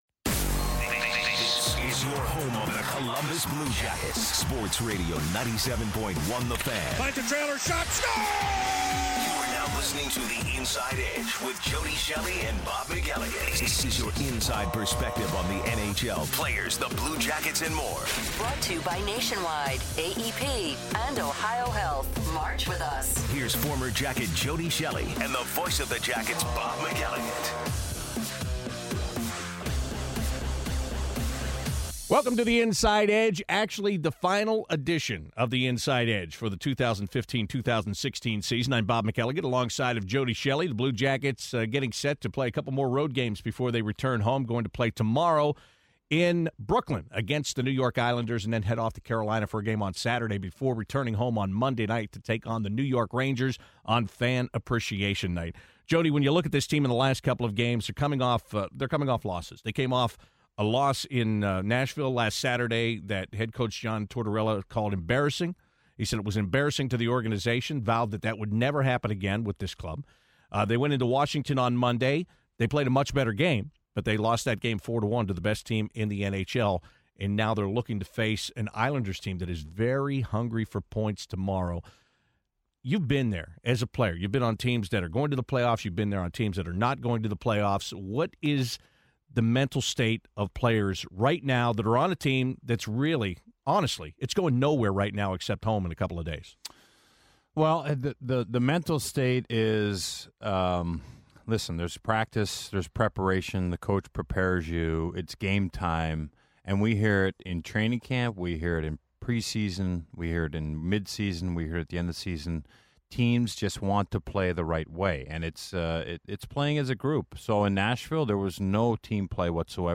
Plus, Zach Werenski also called in to recap his first day with the Lake Erie Monsters.